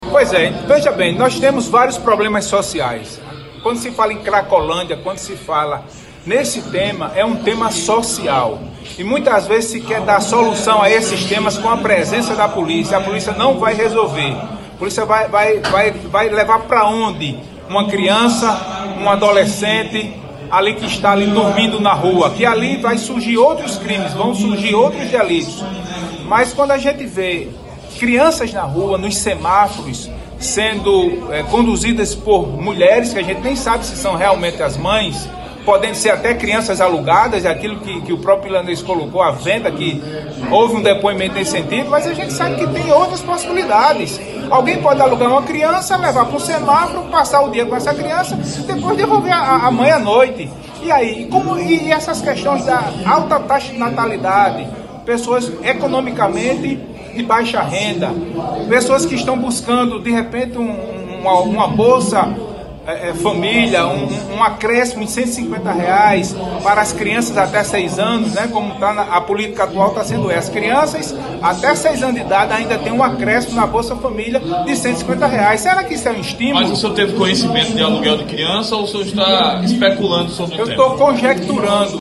Já o vereador Coronel Sobreira (MDB) diz acreditar que existam casos de mães alugando os próprios filhos para pedir esmolas nos semáforos da Capital pessoense.